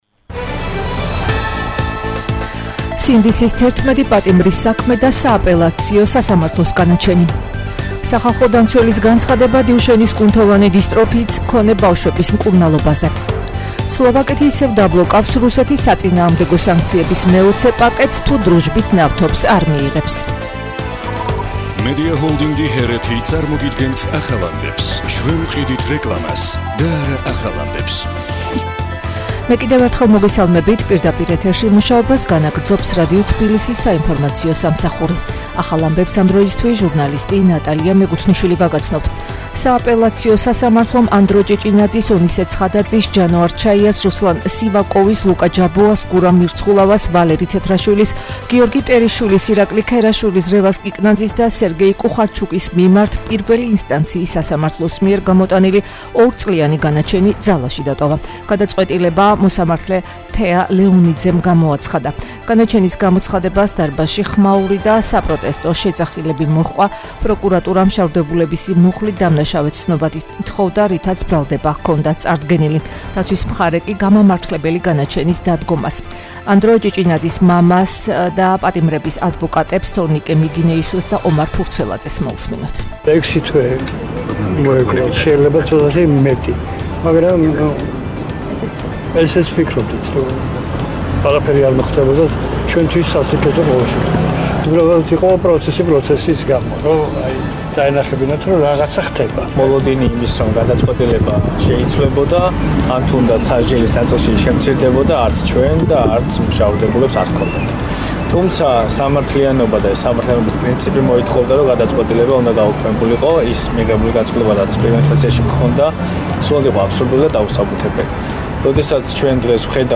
ახალი ამბები 16:00 საათზე